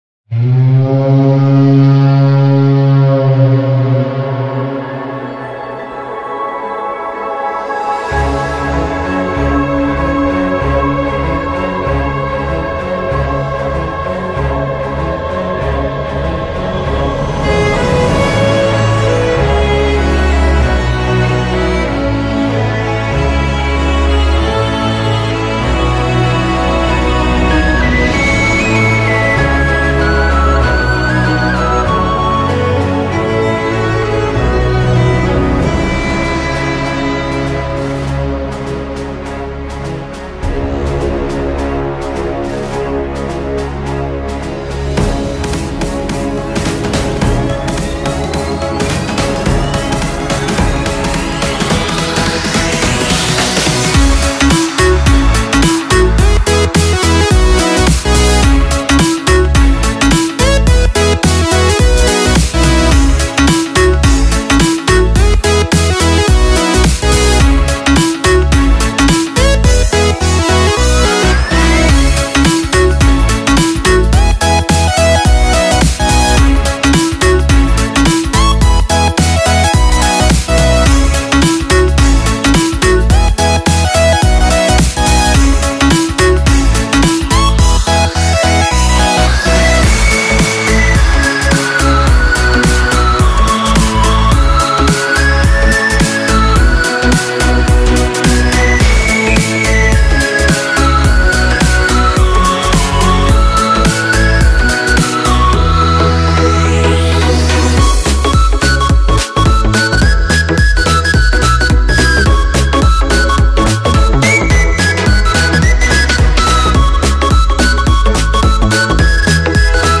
Kategorie Elektroniczne